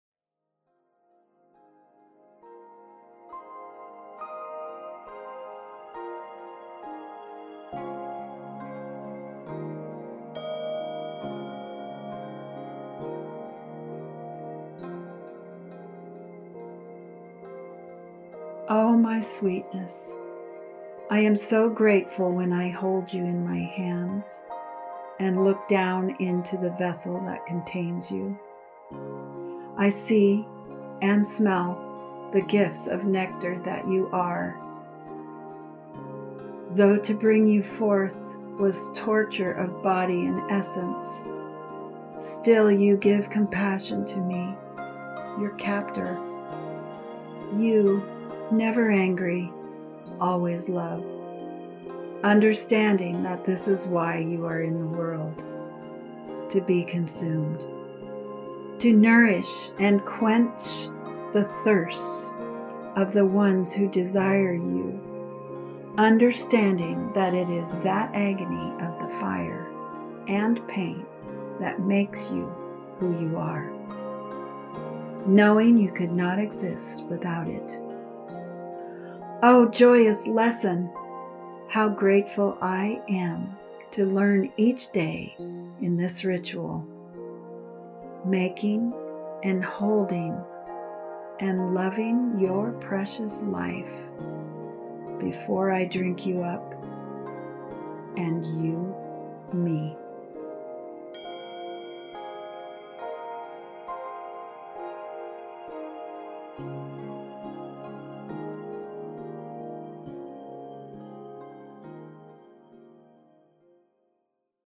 FREE POETRY FOR YOUR SOUL!